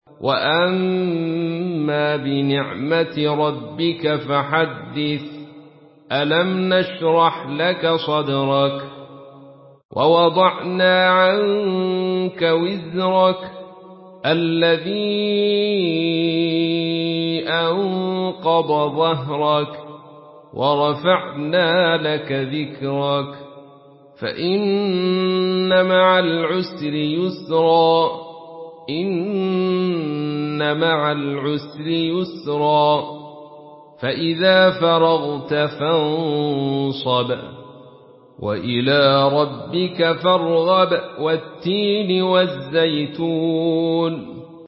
Surah আশ-শারহ MP3 by Abdul Rashid Sufi in Khalaf An Hamza narration.
Murattal